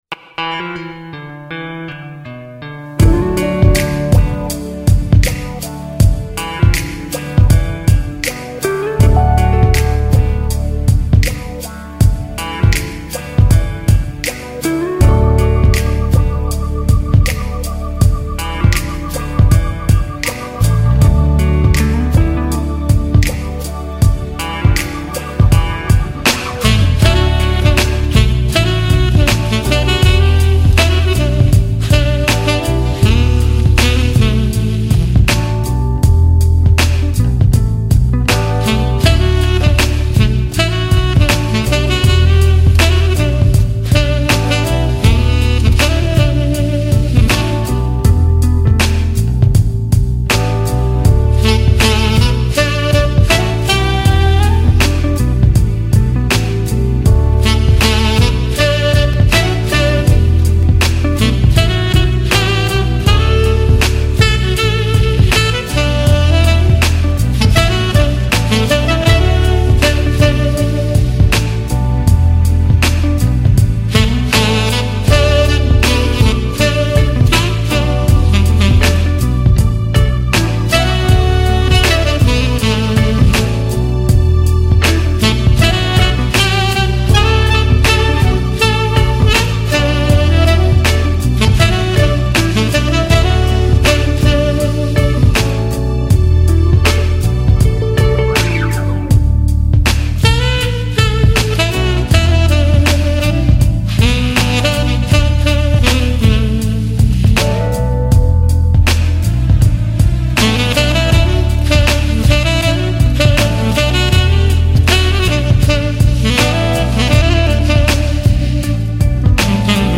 风 格：Smooth Jazz